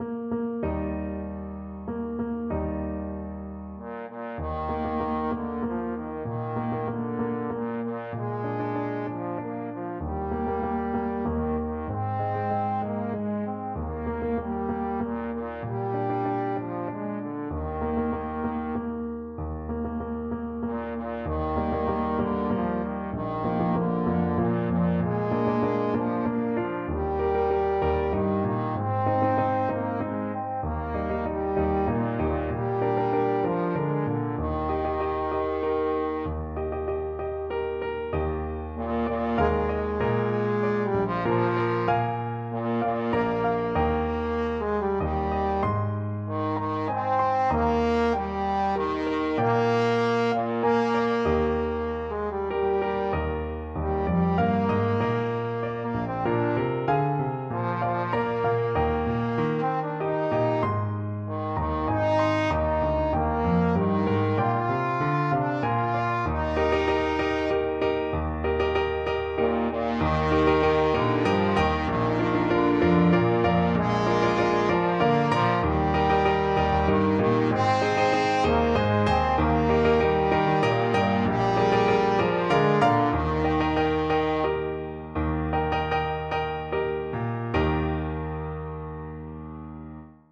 Trombone
Traditional Music of unknown author.
Eb major (Sounding Pitch) (View more Eb major Music for Trombone )
3/4 (View more 3/4 Music)
Andante = c.96
Bb3-F5
Russian